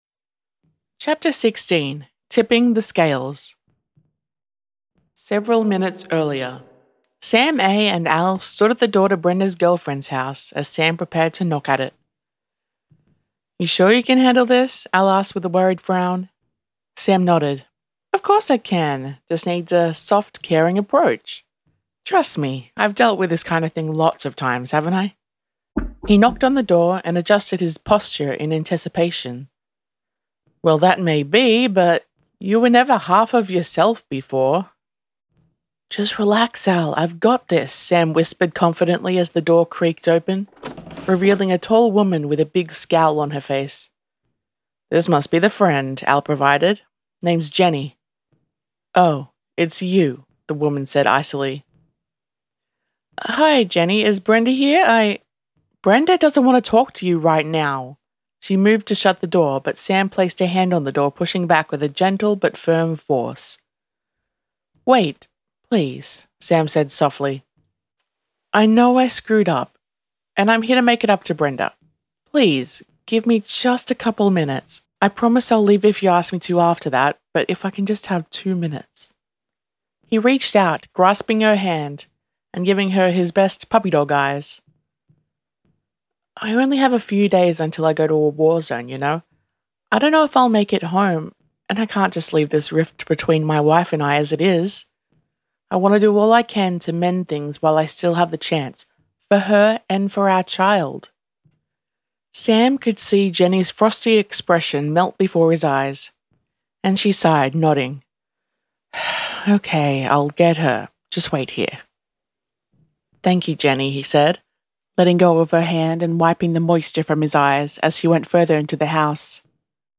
Listen to/download this chapter narrated by the author: